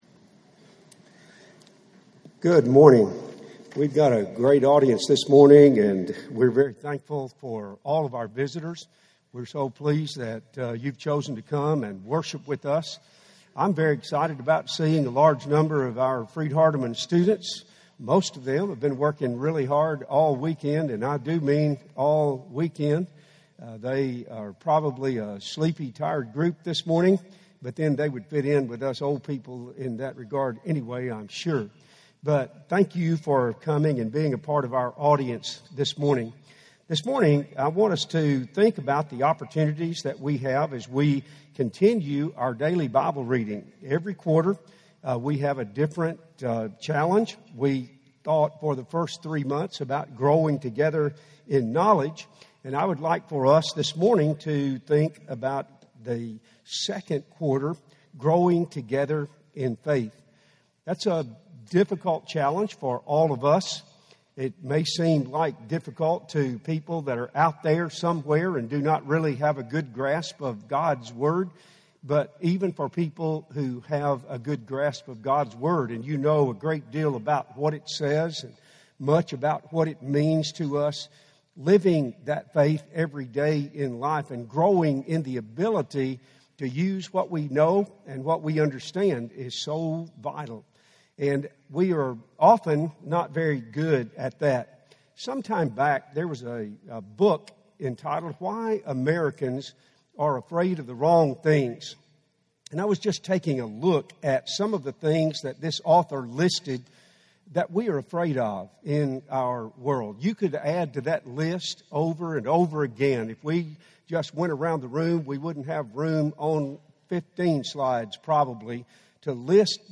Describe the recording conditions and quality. Are We Rushing Through Worship – Henderson, TN Church of Christ